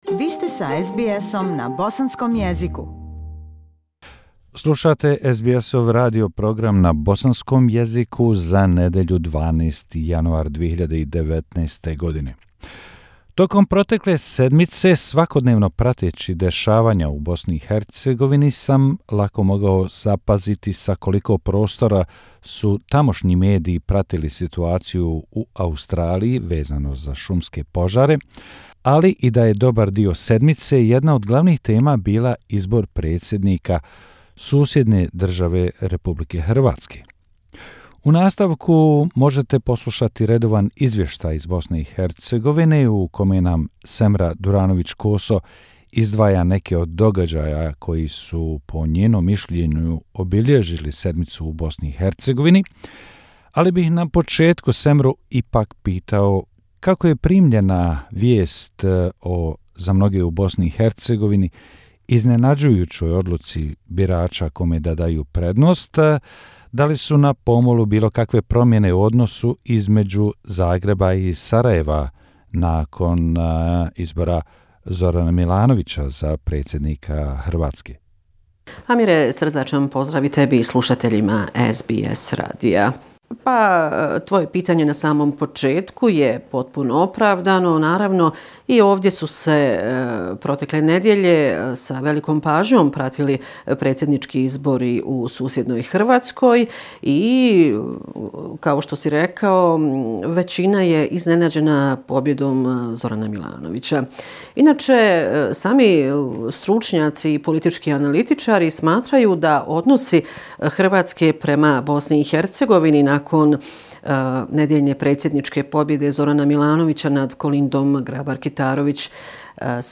Bosnia and Herzegovina - affairs in the country for the last seven day, weekly report January 12, 202O